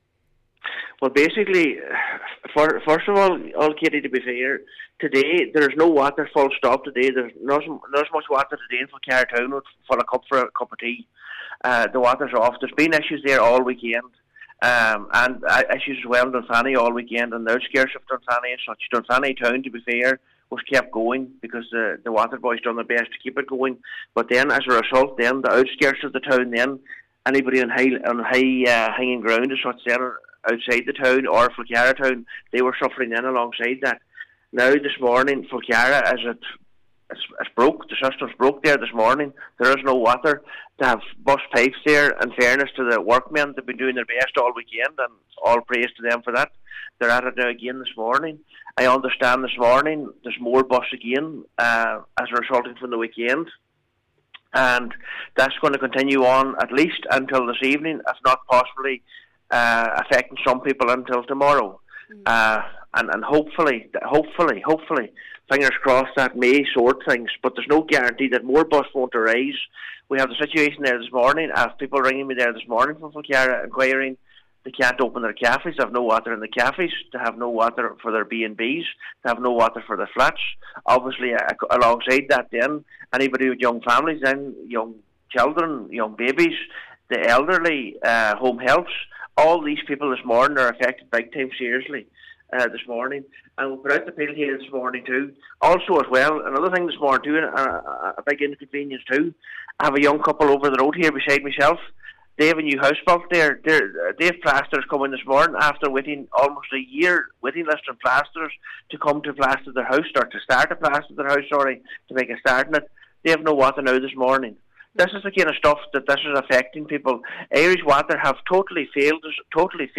Cllr Michael McClafferty says many businesses have been left without water during one of the busiest times of the year.